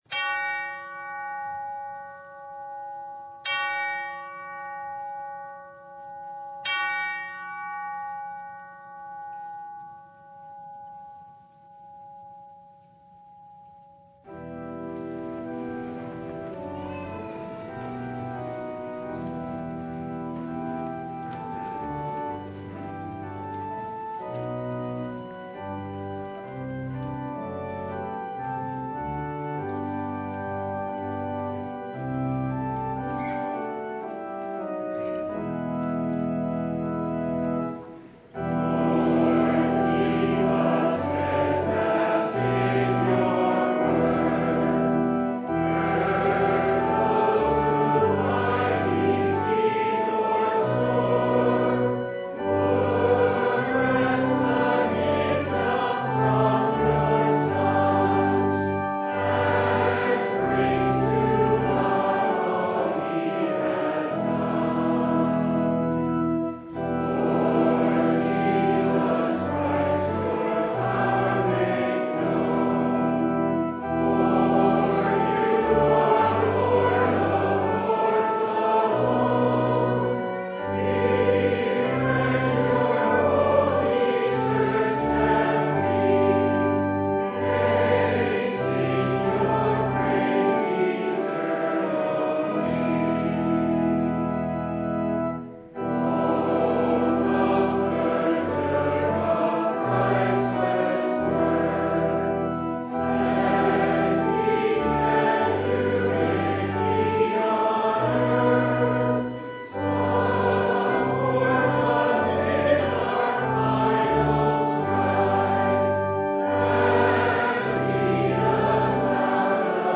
Proverbs 9:1-10 Service Type: Sunday Are you simple?